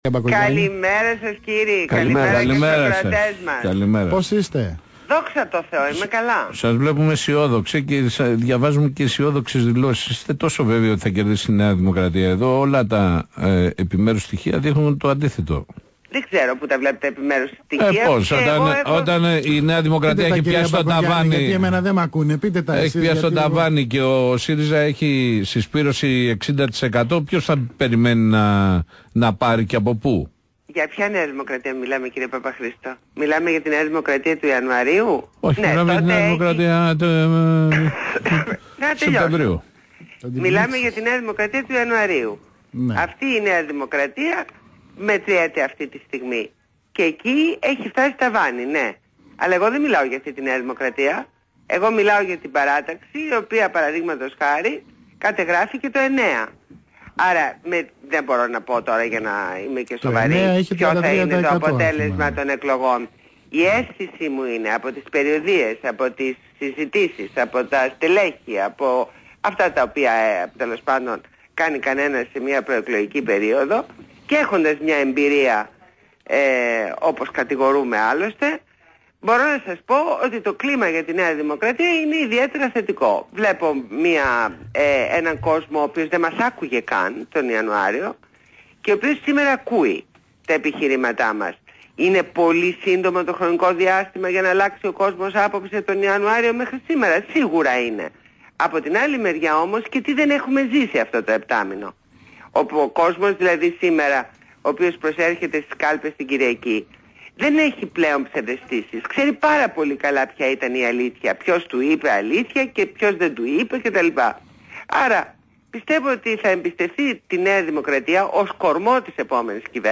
Συνέντευξη στο ραδιόφωνο BHMAfm